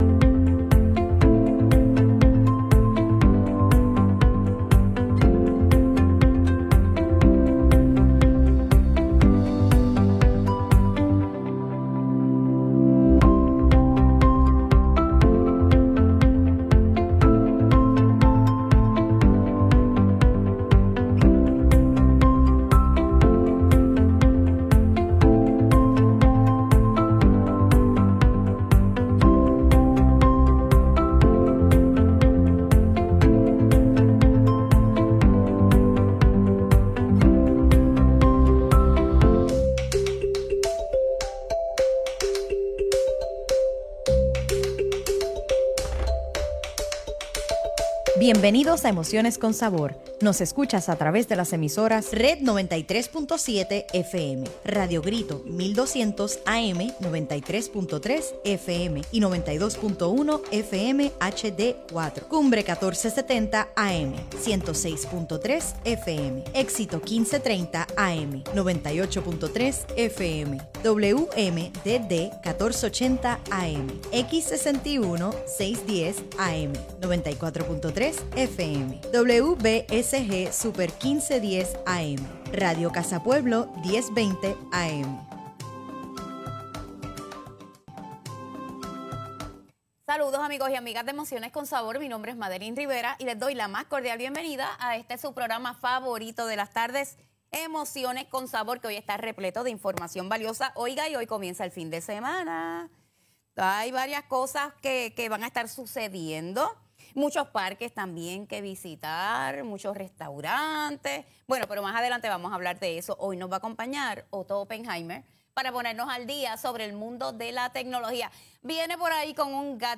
Ya estamos Live!!! hoy en Emociones con Sabor